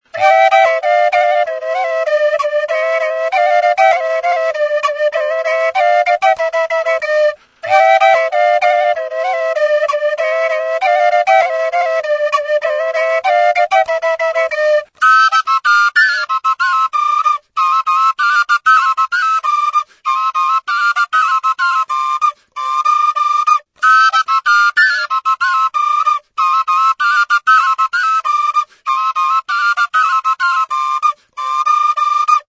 DVOJNICE; Flauta bikoitza | Soinuenea Herri Musikaren Txokoa